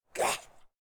femalezombie_attack_03.ogg